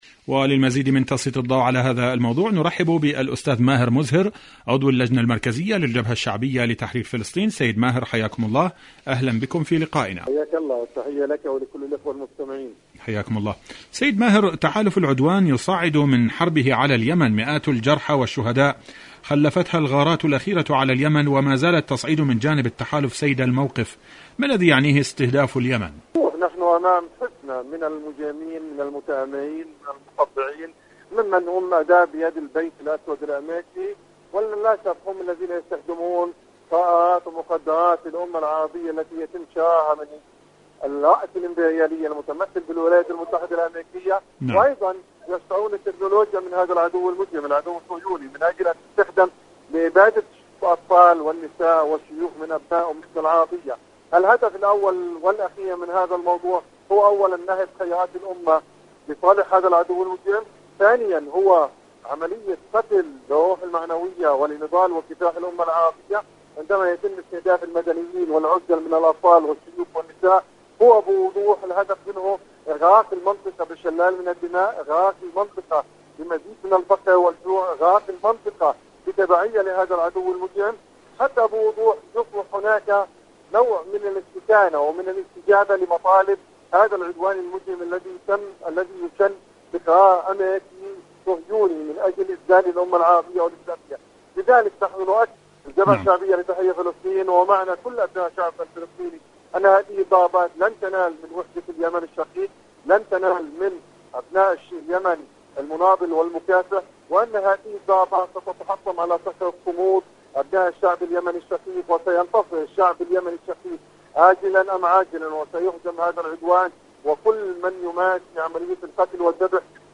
مقابلات برنامج فلسطين اليوم القدس الشريف كيان الاحتلال المسجد الأقصى مقابلات إذاعية برامج إذاعة طهران العربية اليمن فلسطين النصر شاركوا هذا الخبر مع أصدقائكم ذات صلة الردع الإيراني والمقاومة الفلسطينية..